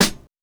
Old School Snare.wav